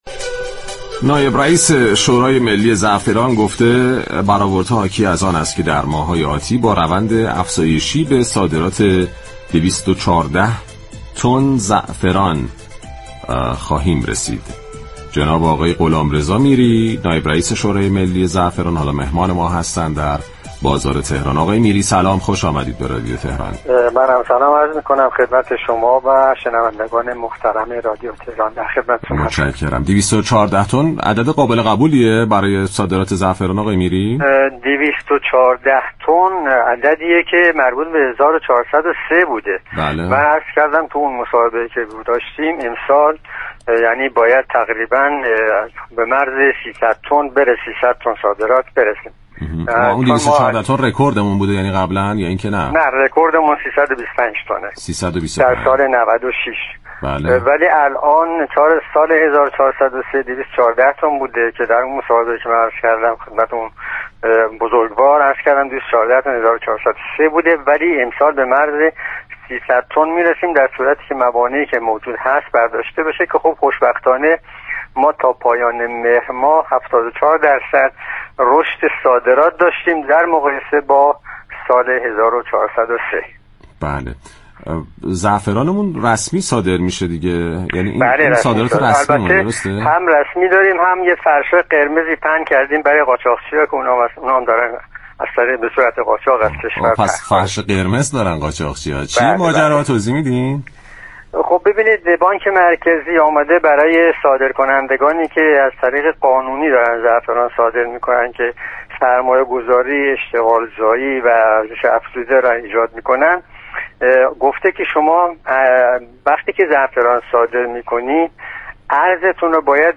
در گفت‌وگو با برنامه «بازار تهران» رادیو تهران